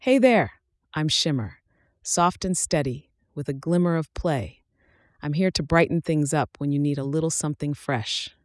NavTalk 提供多种高质量的语音合成风格，您可以通过 voice 参数自由选择数字人音色：
明亮活力女声，适合客服